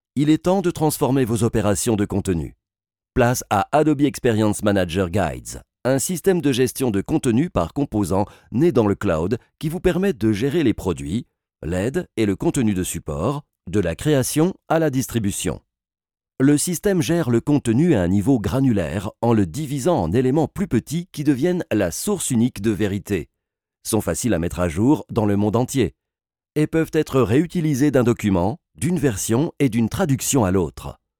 French male voice talent 25 years experience - TV radio adv - documentary - E learning - video
Sprechprobe: eLearning (Muttersprache):